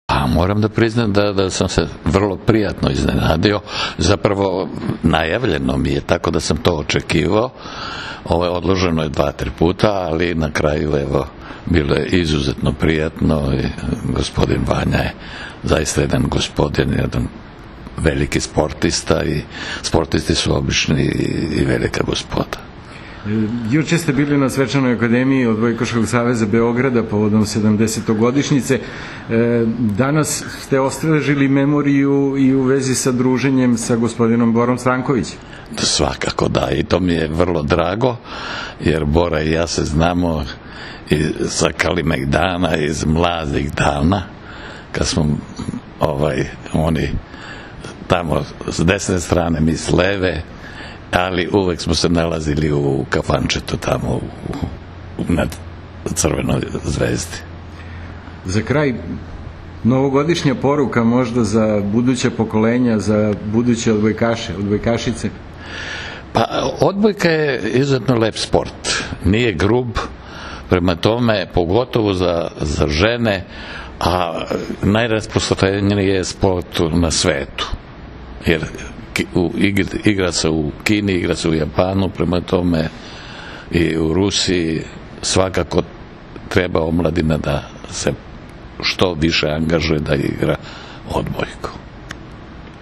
Vanja Udovičić, ministar omladine i sporta Srbije, priredio je danas, u svom kabinetu u Palati Federacije, prijem za legende srpskog sporta Savu Grozdanovića i Borislava Stankovića i uručio im dresove sa brojem 90.
IZJAVA SAVE GROZDANOVIĆA